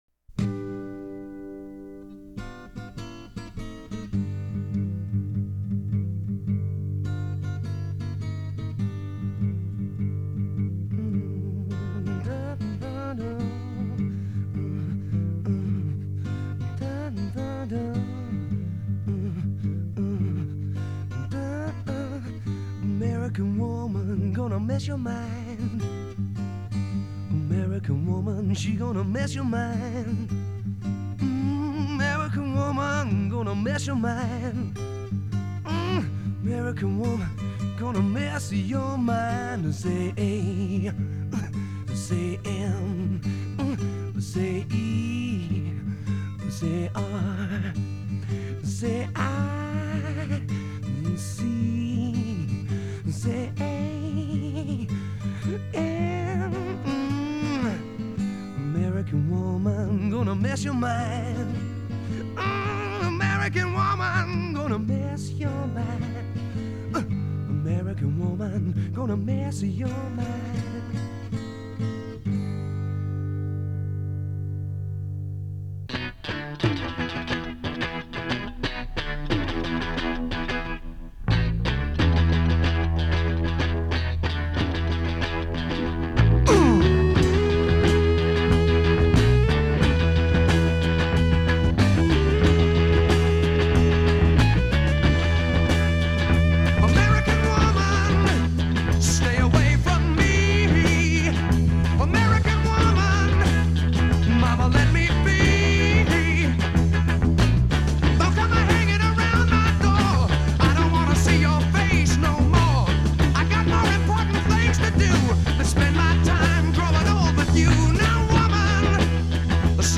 Жанр: Hard Rock, Rock, Psychedelic Rock